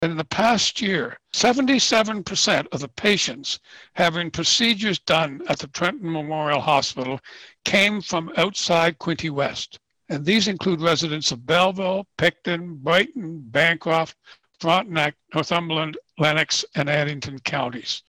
a presentation to Quinte West Council on Monday night